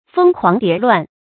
蜂狂蝶亂 注音： ㄈㄥ ㄎㄨㄤˊ ㄉㄧㄝ ˊ ㄌㄨㄢˋ 讀音讀法： 意思解釋： 舊指男女間行為放蕩。